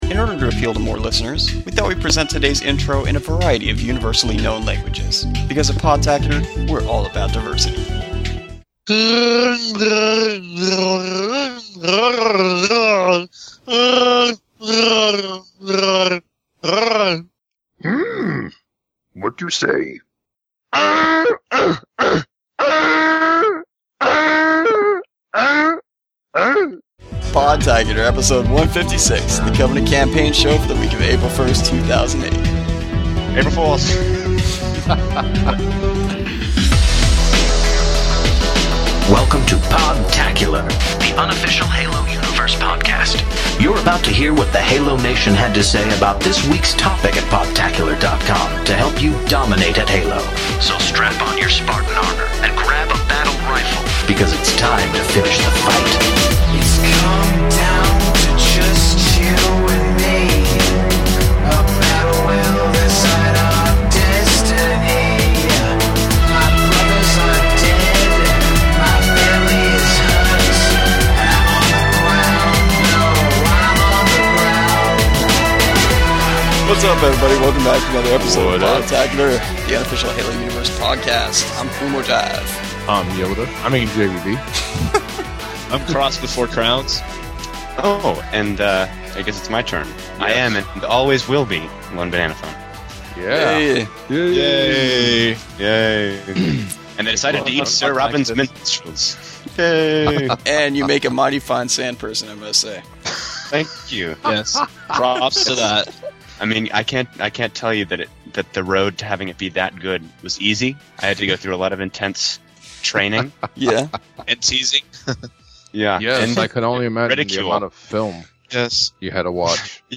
Show’s just under an hour and has some crazy outtakes at the end about the making of the intro.